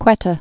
(kwĕtə)